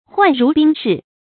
涣如冰释 huàn rú bīng shì 成语解释 犹言涣然冰释。
ㄏㄨㄢˋ ㄖㄨˊ ㄅㄧㄥ ㄕㄧˋ